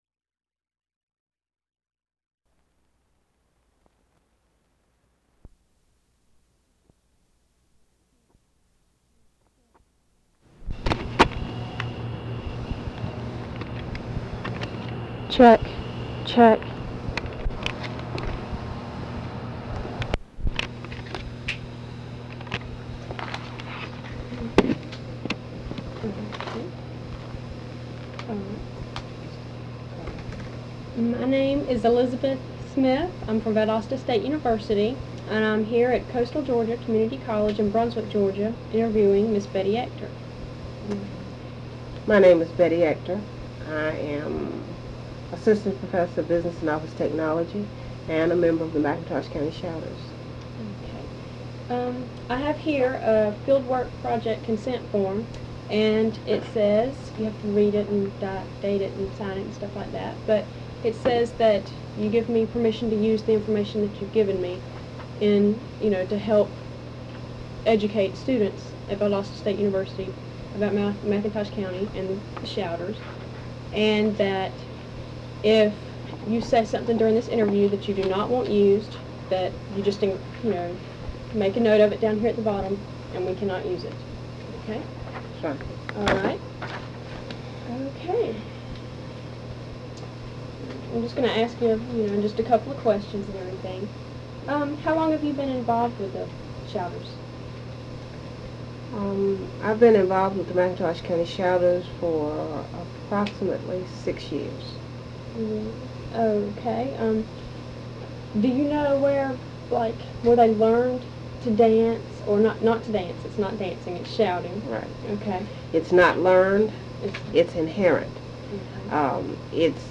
Audio file from cassette tape. Part of the South Georgia Folklife Project at Valdosta State University Archives and Special Collections. Topics include McIntosh County Shouters.